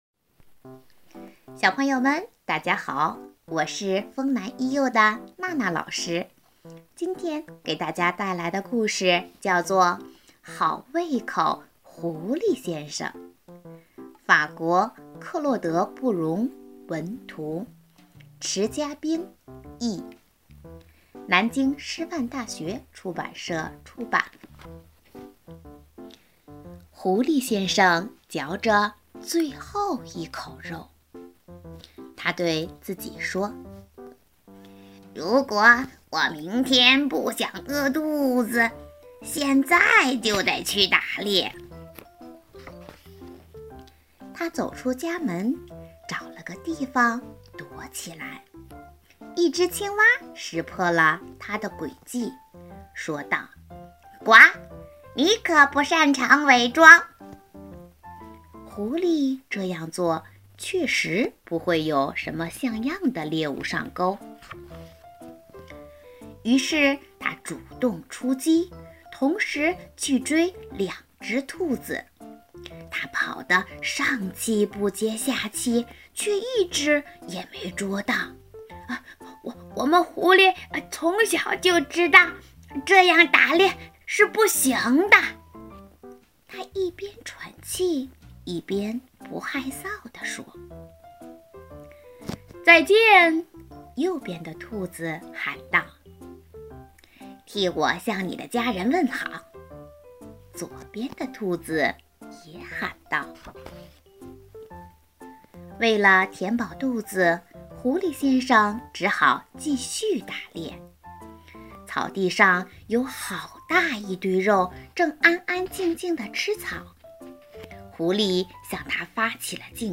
【讲座】丰南图书馆少儿故事会第六十二期—《好胃口，狐狸先生》